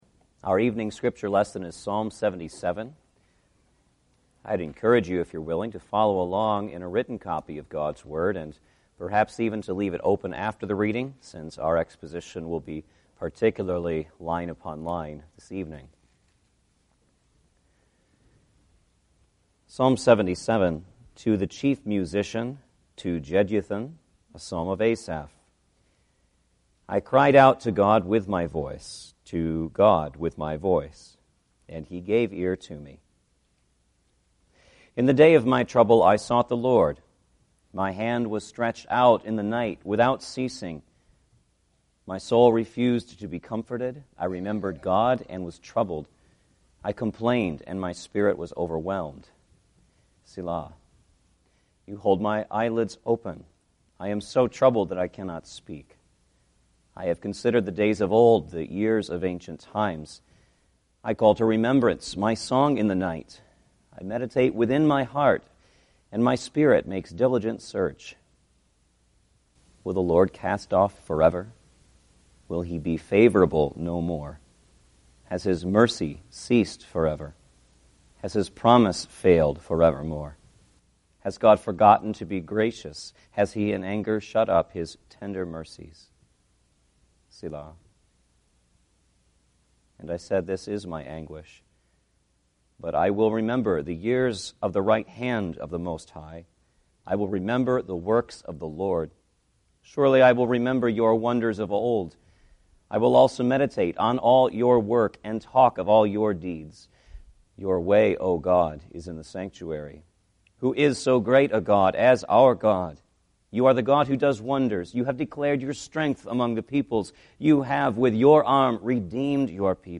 Psalms of Asaph Passage: Psalm 77 Service Type: Sunday Evening Service « Hello Again